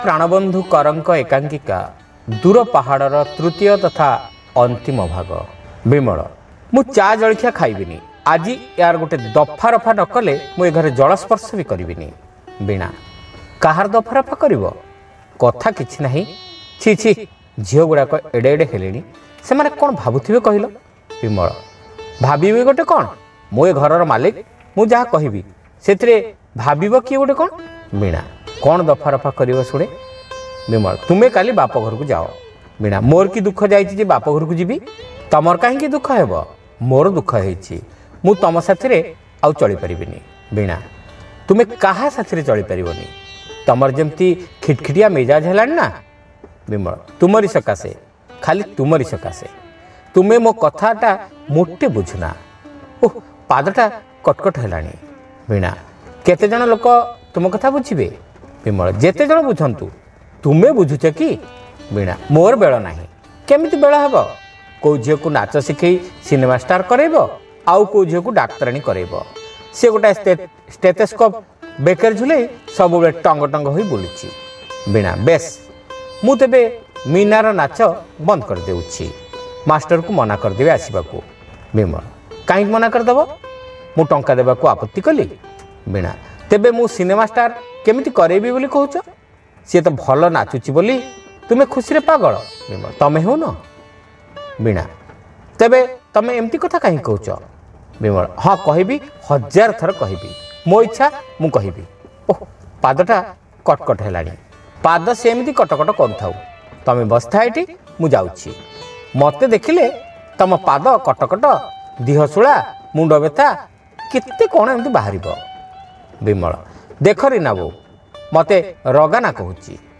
ଶ୍ରାବ୍ୟ ଏକାଙ୍କିକା : ଦୂର ପାହାଡ଼ (ତୃତୀୟ ଭାଗ)